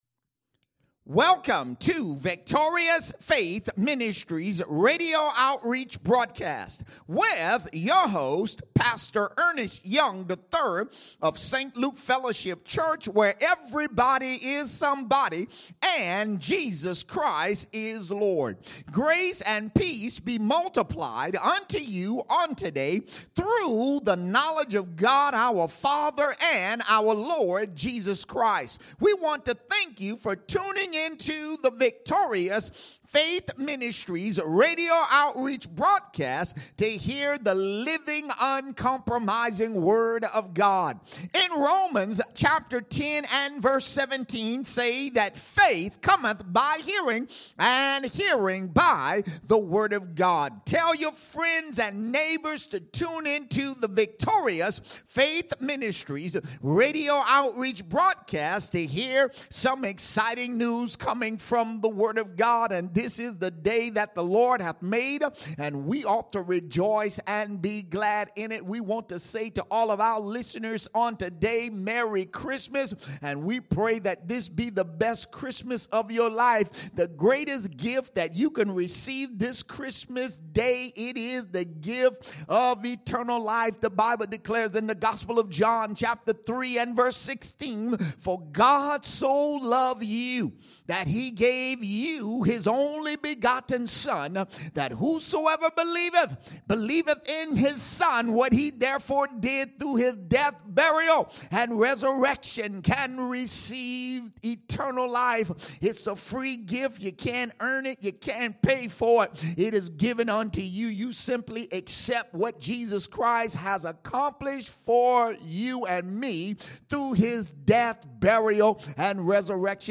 Sermons - St. Luke Fellowship Church